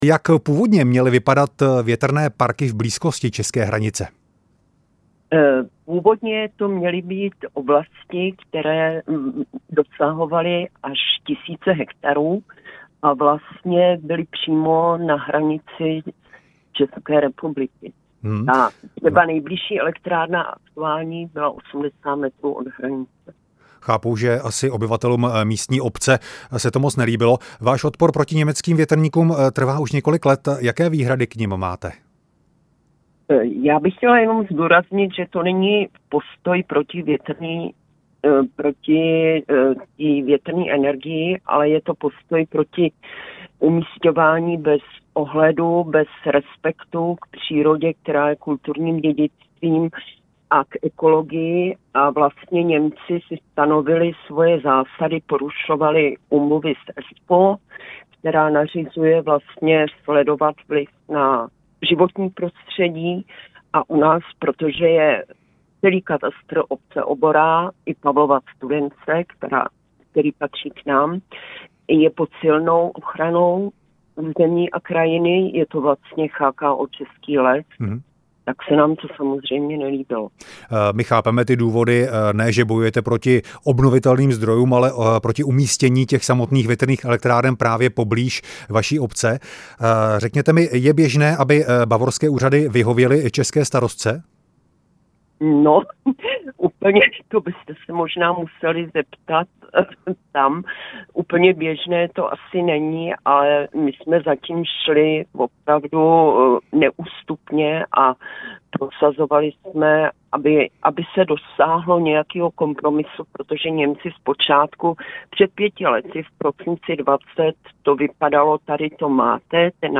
Rozhovor se starostkou Obory Danou Lesak